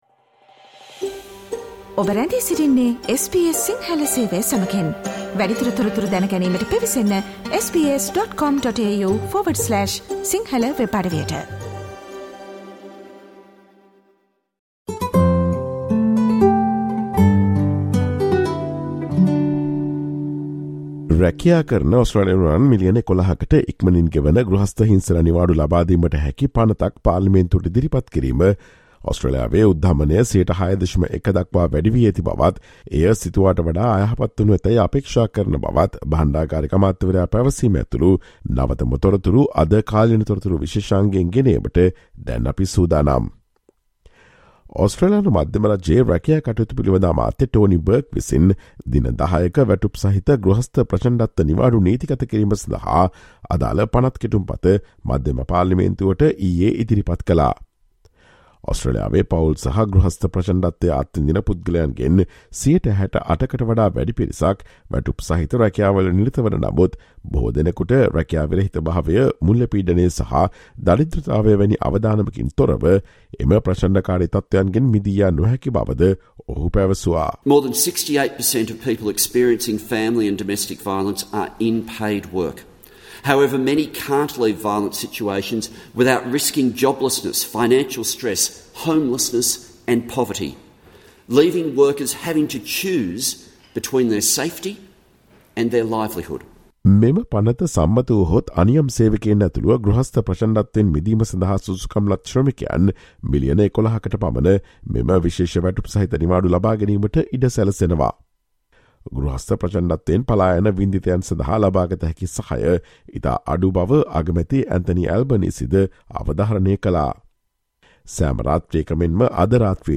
Listen to the SBS Sinhala Radio's current affairs feature broadcast on Friday, 29 July with information about what happened in the Australian Federal Parliament yesterday.